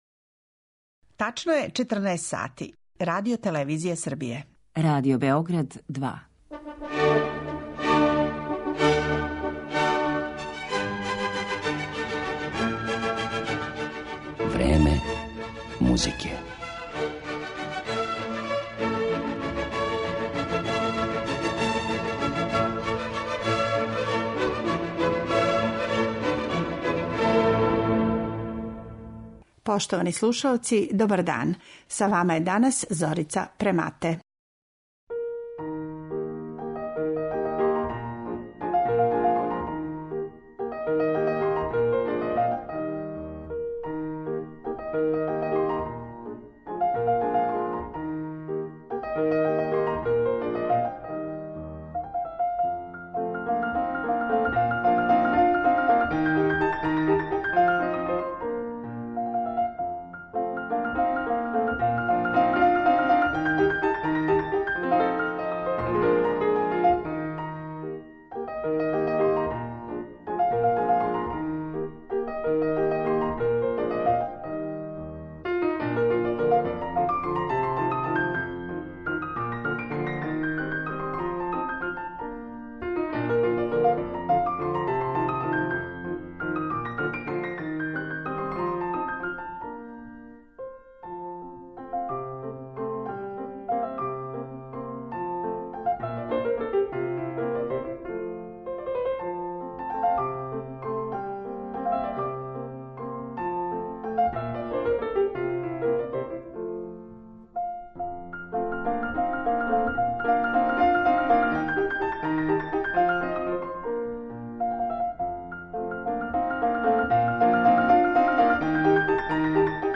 Чућете дела Марије Шимановске, Фани Менделсон, Кларе Шуман, Сесил Шаминад и Доре Пјачевић.